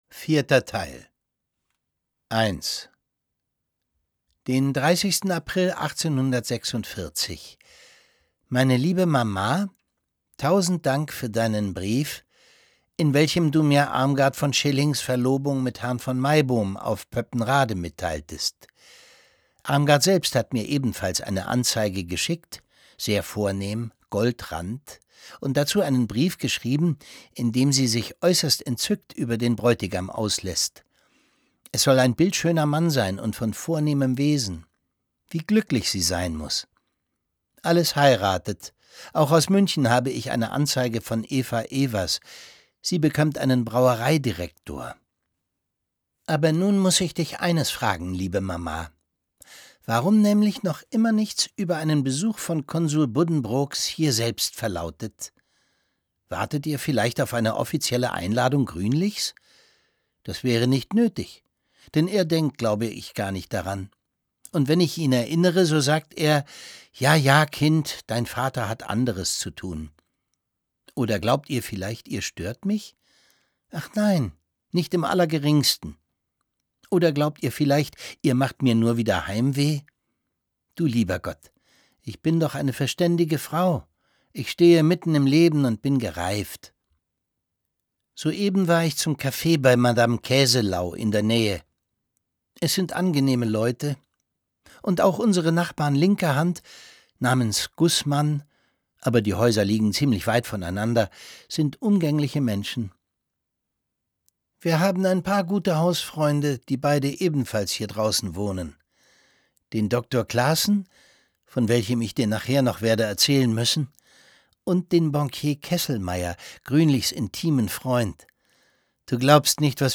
Von dort schreibt sie auch den Brief, der den vierten Teil des Romans eröffnet. Es liest Thomas Sarbacher.